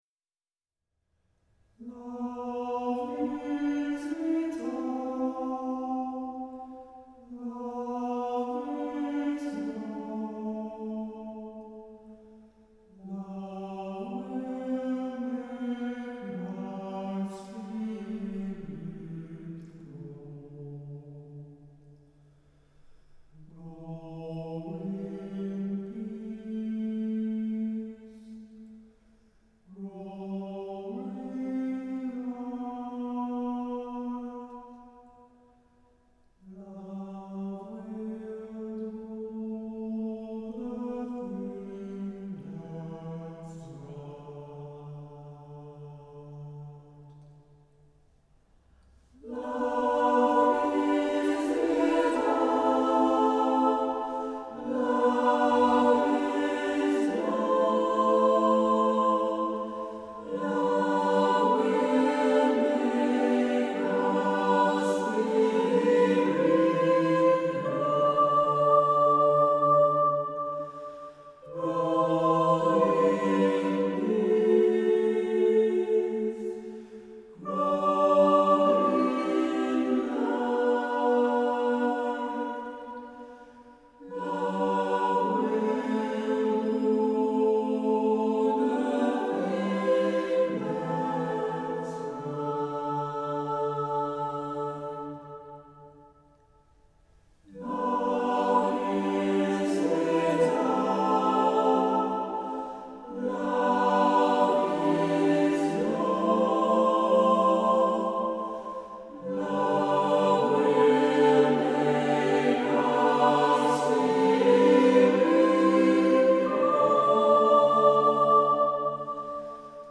piano
vocal ensemble